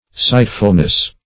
Search Result for " sightfulness" : The Collaborative International Dictionary of English v.0.48: Sightfulness \Sight"ful*ness\, n. The state of being sightful; perspicuity.